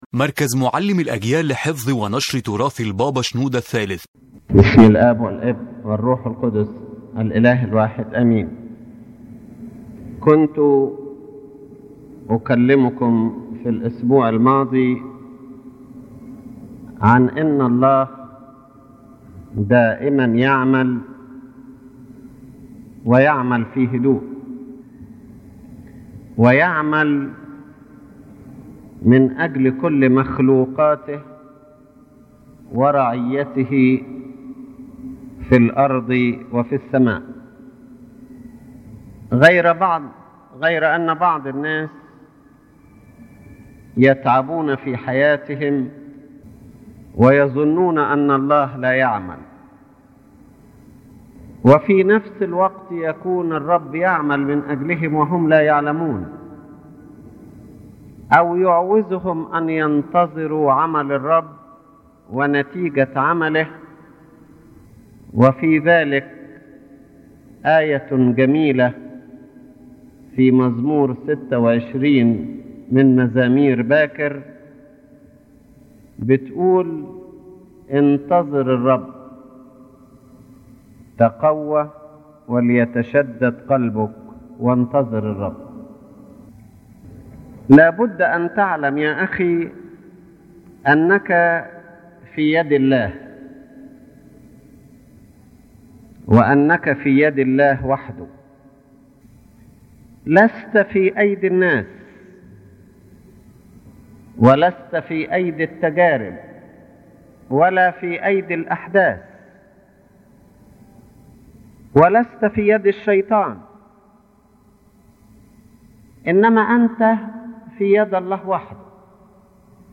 The lecture revolves around calling the person to complete trust in God’s work and waiting for His plan with patience and faith, because God always works—even if we do not realize it—and chooses the proper timing according to His divine wisdom.